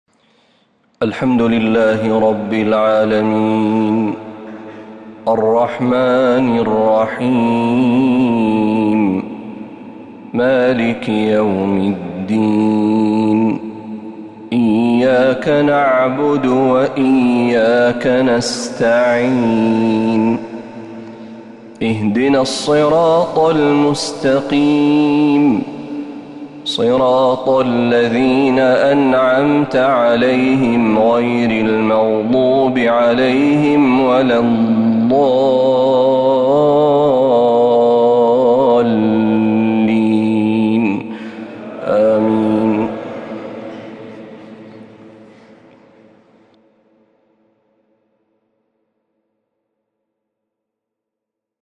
من الحرم النبوي